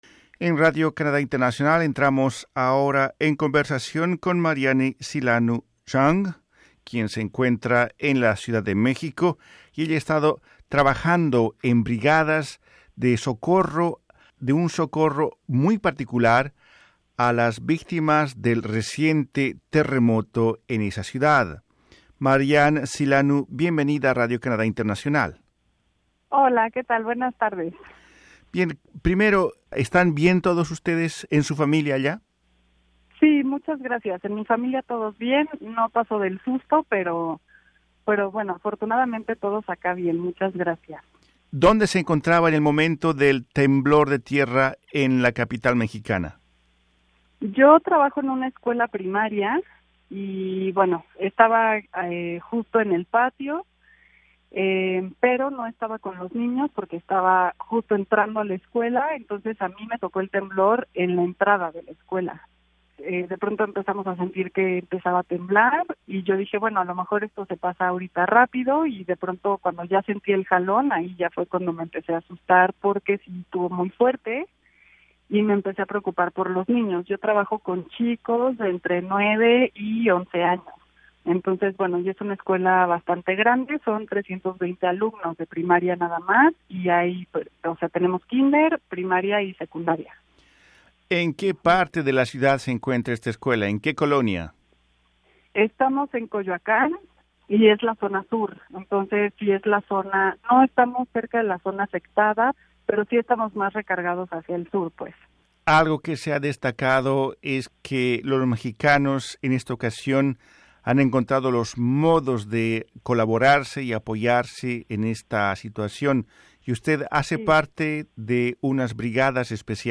© Wiki Commons En conversación con Radio Canadá Internacional, ella explica que tras el derrumbe de viviendas y edificios, su reacción fue buscar la forma de ayudar a las personas afectadas.